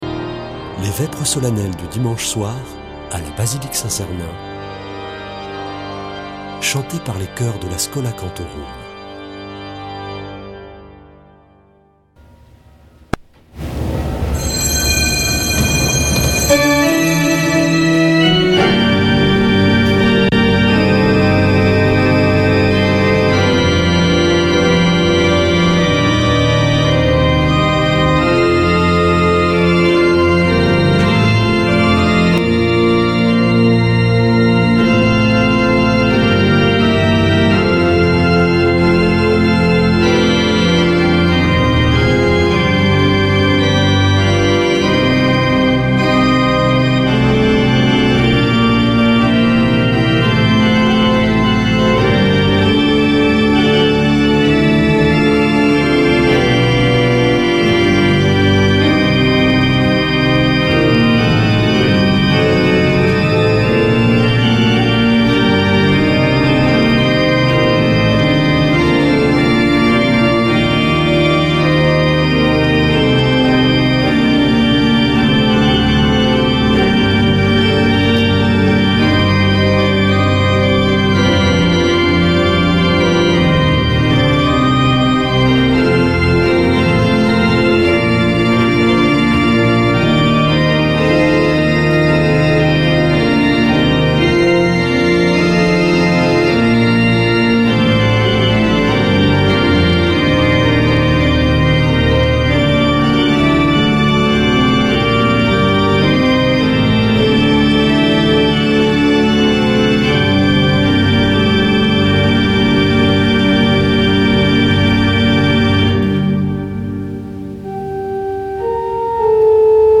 Vêpres de Saint Sernin du 05 nov.
Schola Saint Sernin Chanteurs